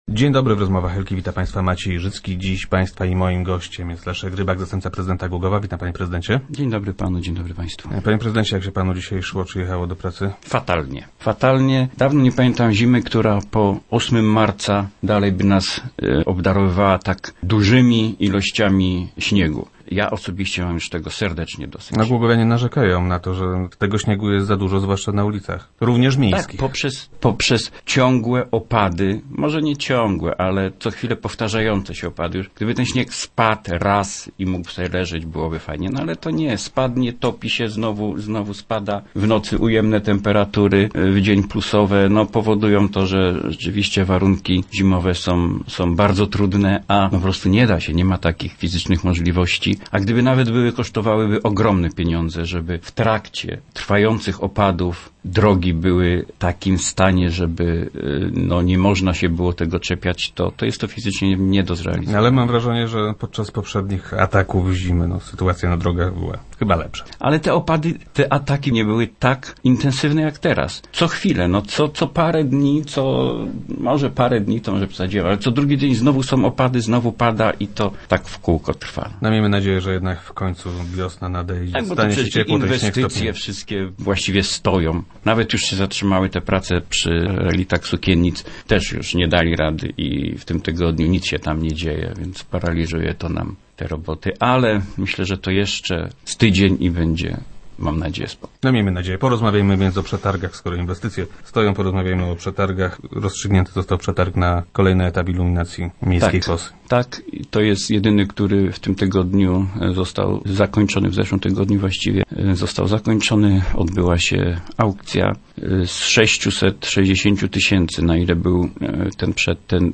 Podczas tego spotkania przekazał on pismo od pracowników, zatrudnionych w zakładzie, z wnioskiem o utworzenie linii autobusowej, z której mogliby korzystać - mówił w Rozmowach Elki wiceprezydent Rybak.